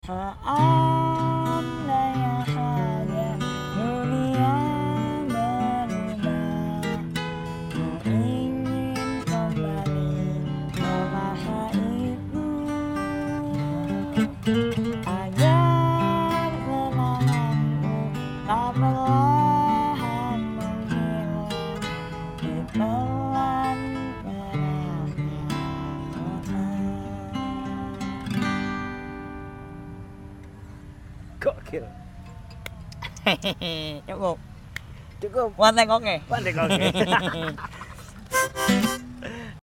Versi Akustik.